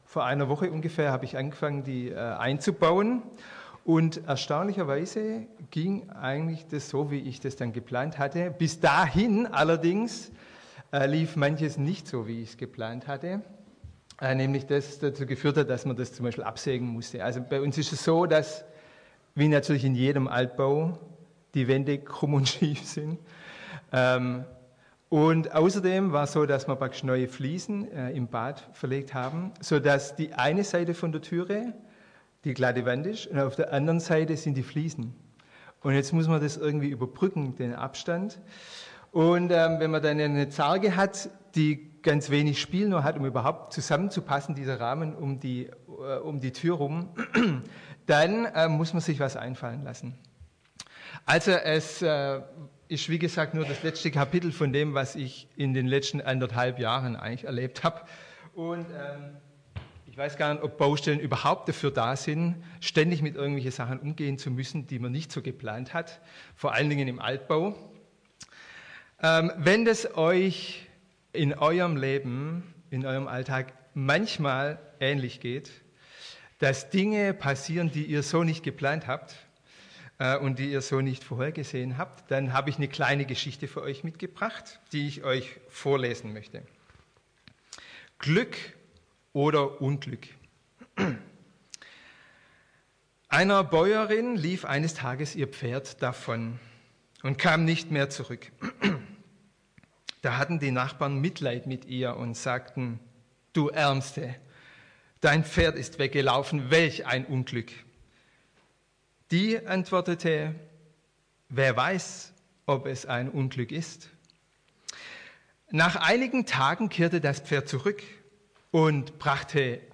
Neue Menschen: Jesus öffnet das Herz ~ Predigten aus der Fuggi